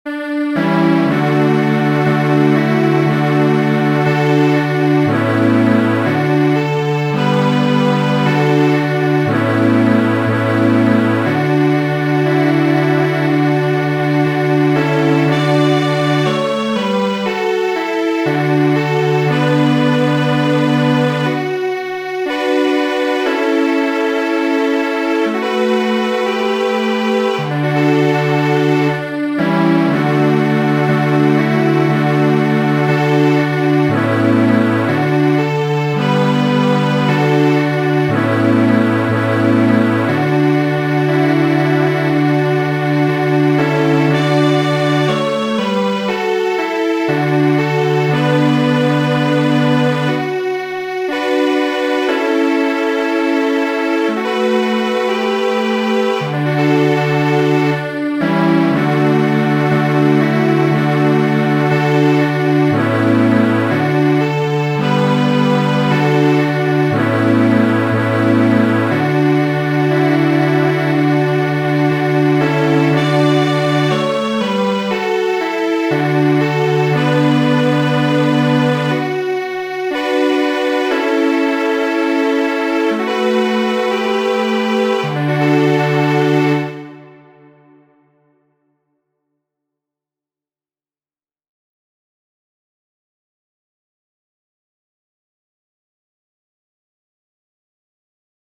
Mi mem havis la bonŝancon akompani ĥoron
da makedoninoj kaj japanino, kiuj kantis popolajn kantojn de tiuj etnoj. La aferon oni elsendis televide.